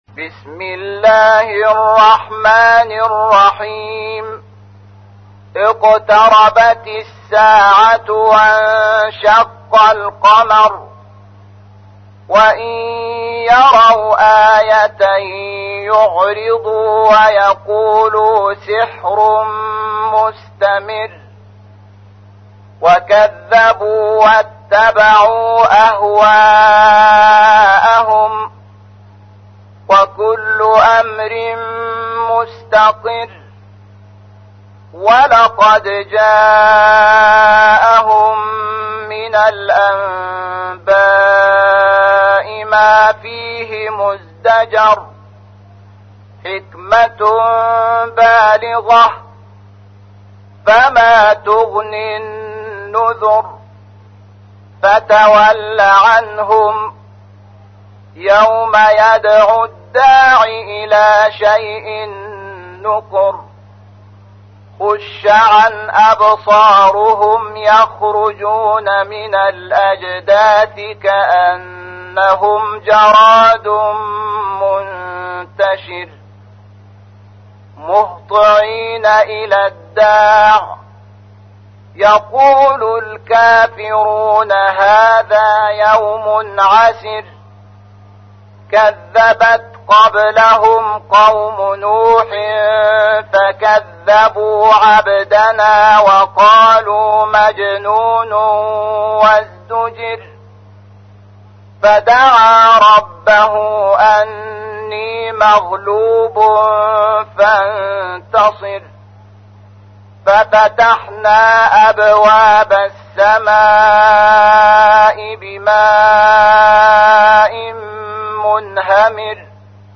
تحميل : 54. سورة القمر / القارئ شحات محمد انور / القرآن الكريم / موقع يا حسين